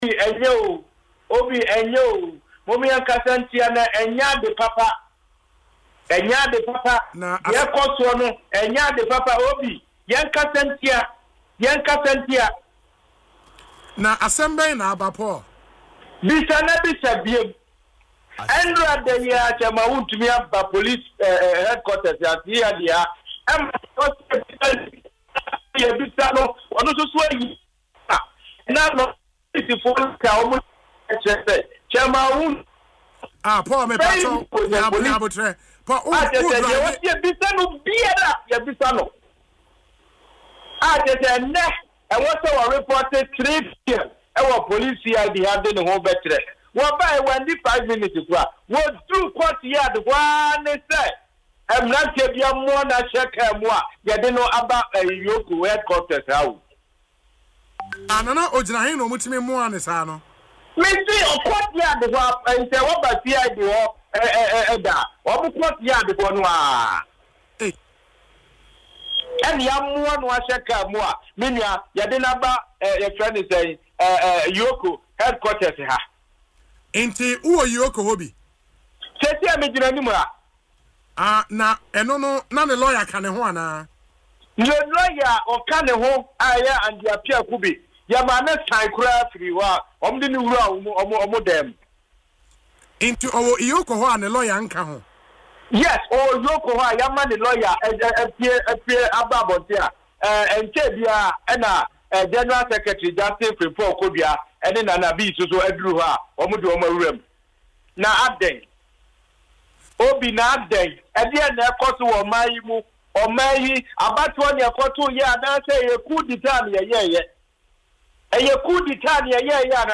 In an interview on Asempa FM’s Ekosii Sen